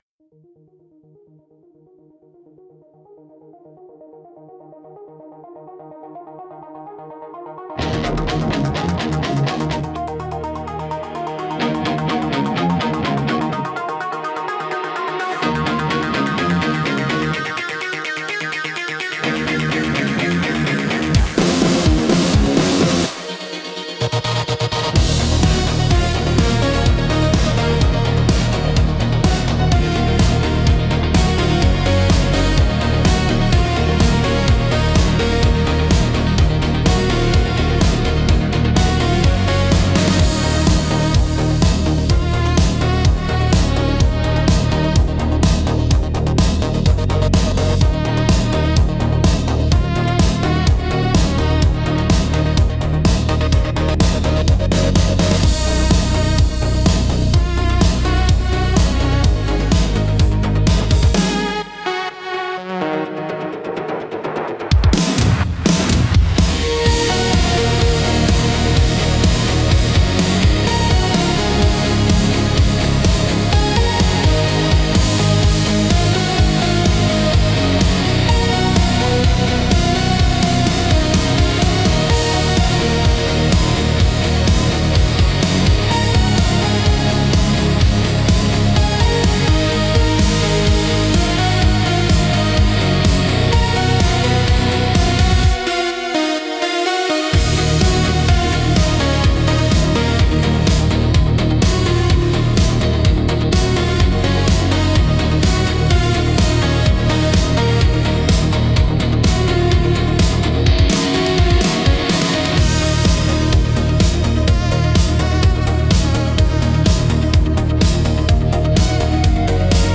Instrumental - Snakebite Shuffle